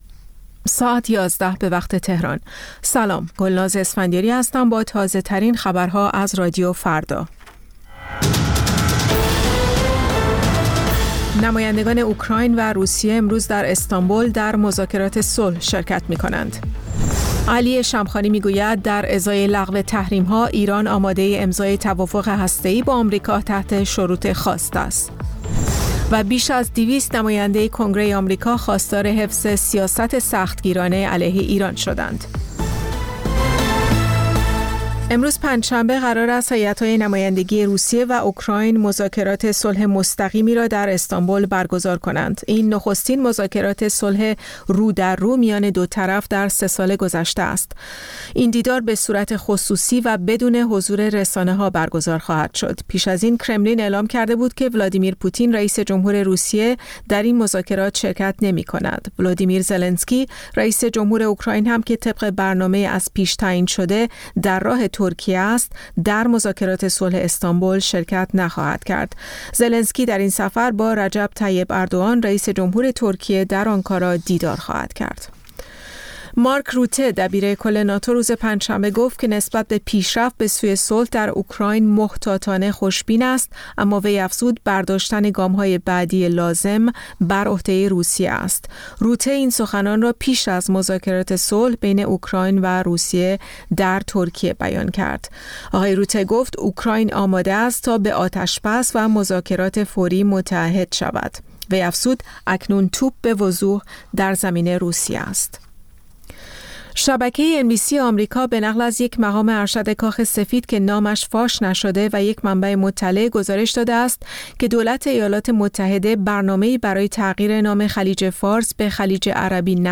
سرخط خبرها ۱۱:۰۰